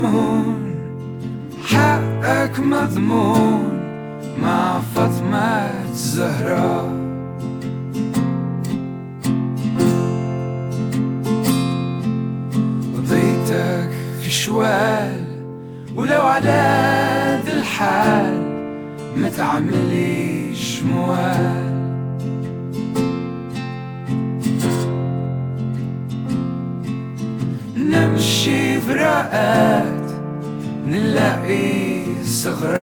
This song is produced by AI tools